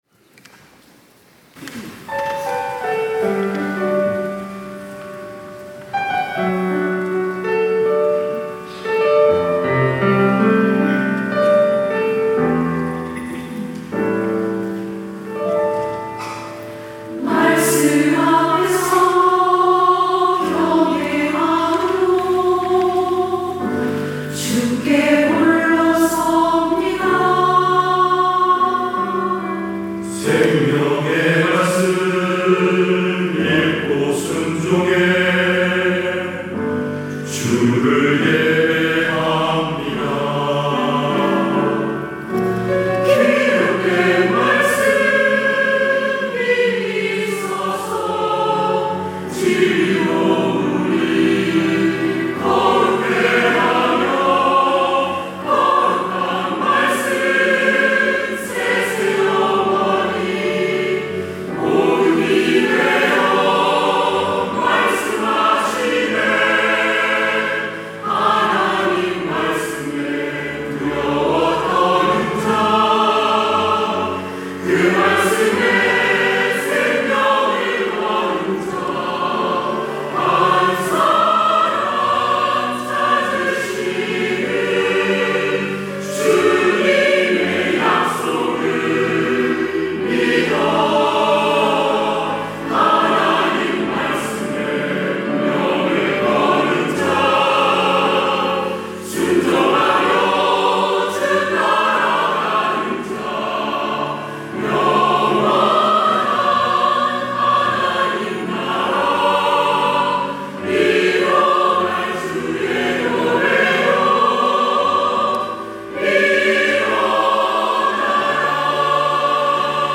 할렐루야(주일2부) - 말씀 앞에서
찬양대